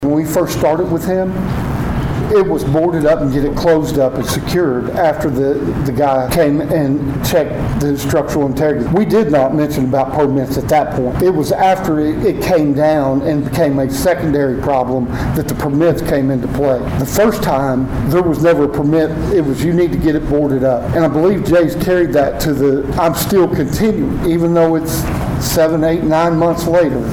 At a Tuesday evening city council meeting
City Manager Jerry Eubanks talks about the conversation